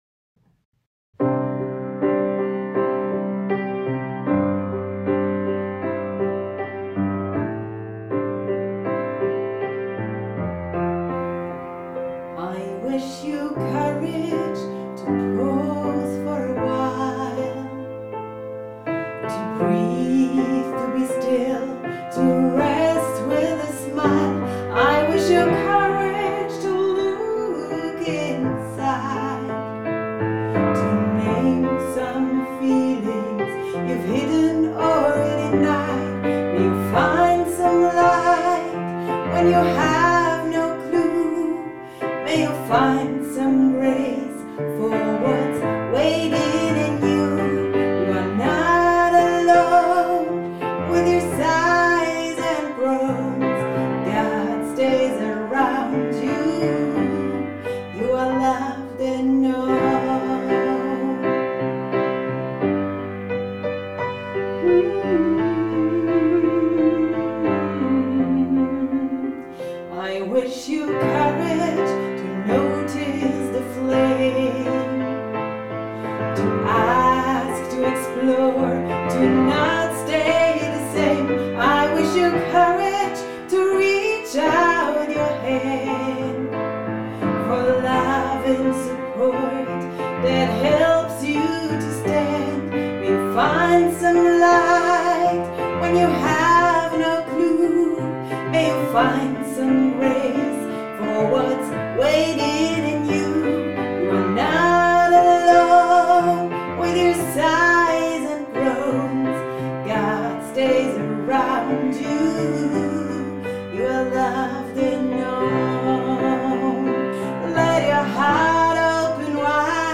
song with piano accompaniment